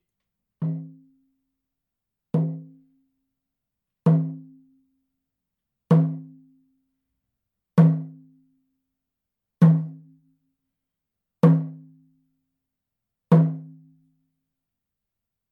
ネイティブ アメリカン（インディアン）ドラム NATIVE AMERICAN (INDIAN) DRUM 12インチ（elk アメリカアカシカ・ワピチ）
ネイティブアメリカン インディアン ドラムの音を聴く
乾いた張り気味の音です